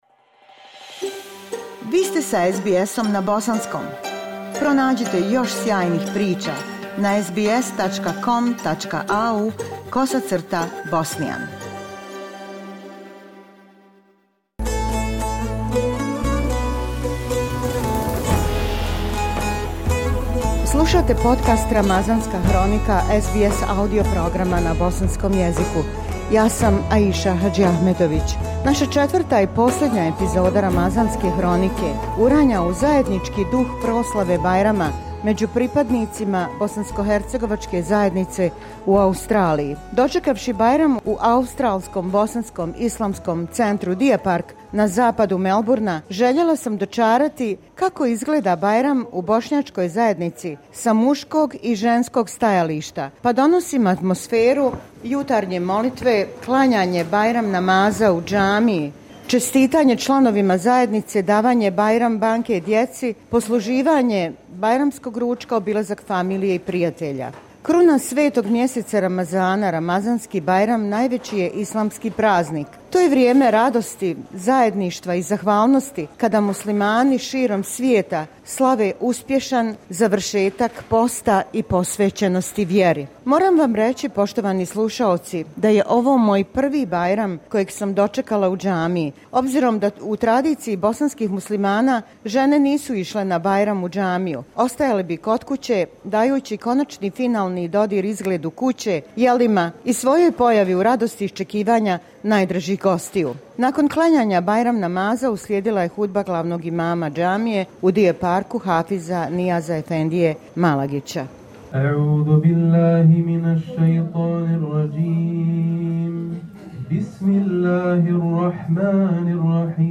Četvrta i poslednja epizoda Ramazanske hronike uranja u zajednički duh proslave Bajrama među pripadnicima bosanskohercegovačke zajednice u Australiji. Dočekavši Bajram u ABIC Deer Park, u Melbourneu, željela sam istražiti kako izgleda Bajram sa muškog i ženskog stajališta.
Ova epizoda donosi atmosferu jutarnje molitve, klanjanja Bajram- namaza, čestitanja članovima zajednice, posluživanja kafe i kolača nakon zvanične ceremonije u samoj džamiji.